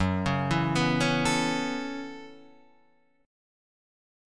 F#m11 Chord